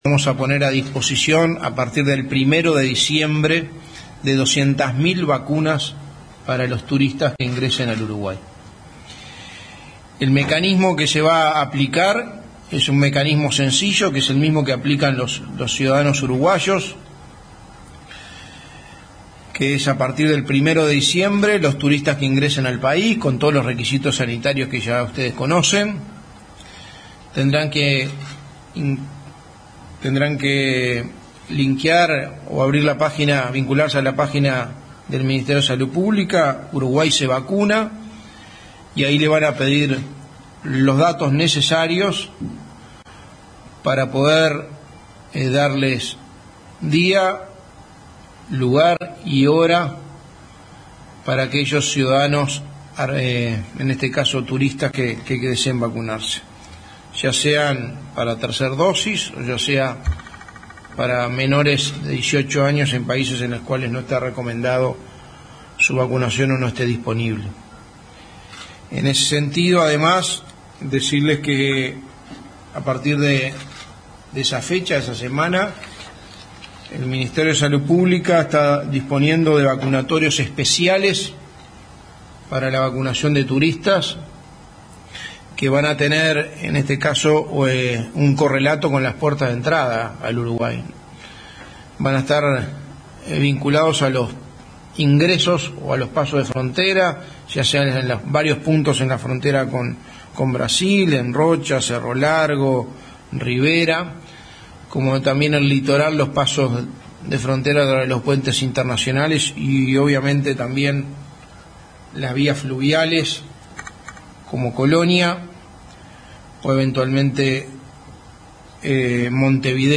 El gobierno contará con 200.000 dosis de Pfizer para extranjeros no residentes desde el 1 de diciembre, informó el secretario de la Presidencia, Álvaro Delgado, en rueda de prensa.
Escuche a Álvaro Delgado aquí